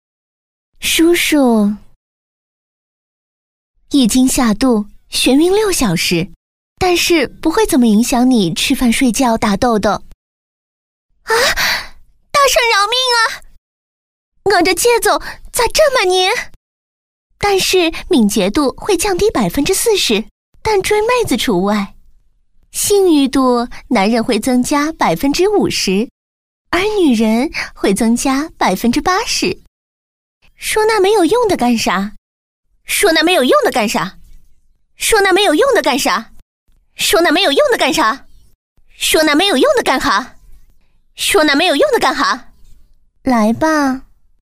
女130-游戏CG【游戏语音-温柔】
女130-游戏CG【游戏语音-温柔】.mp3